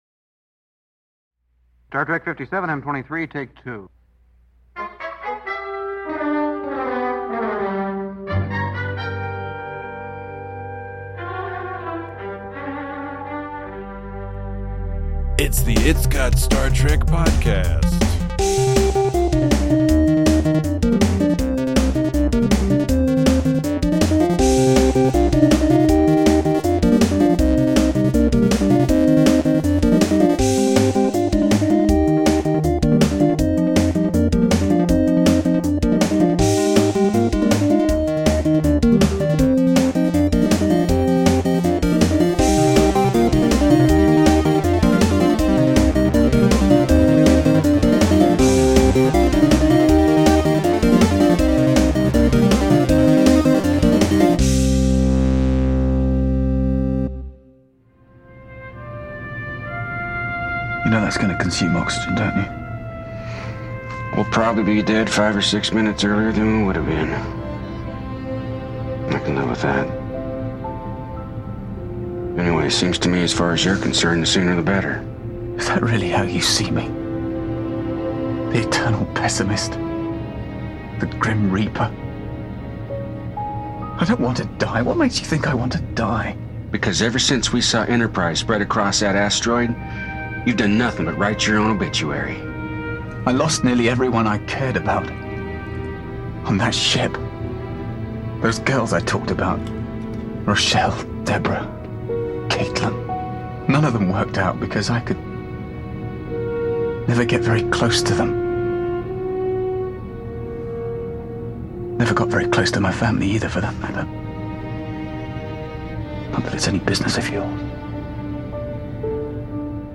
Trip and Malcolm realize they both dated the same gal. Join your increasingly chilly hosts as they discuss early-series character development, the lengths required to get two male characters to open up to each other, and that bizarre "stinky" business.
We dive right into a detail-rich discussion of this episode of Star Trek: Enterprise, so if you haven’t had a chance to see it yet – beware!